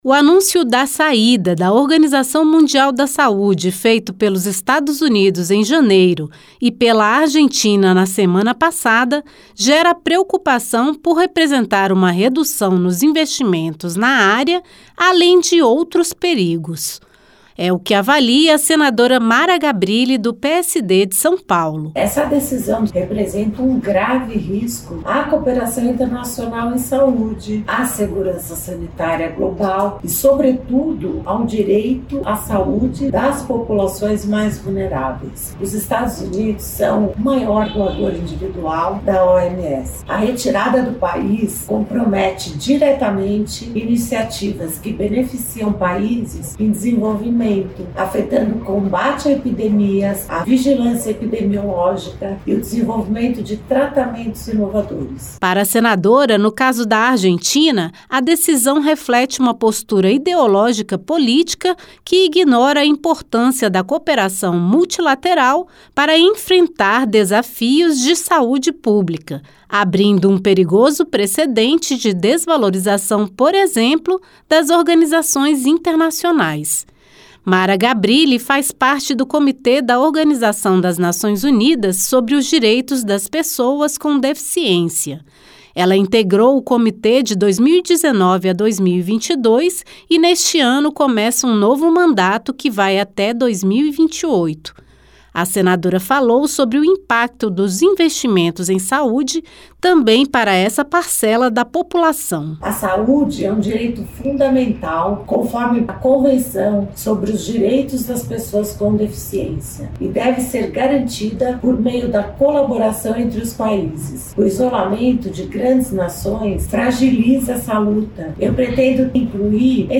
Retirada dos países anunciada há pouco tempo deve se concretizar no período de um ano. Senadora Mara Gabrilli (PSD-SP) adverte para risco na cooperação internacional em saúde, segurança sanitária e direito das pessoas vulneráveis.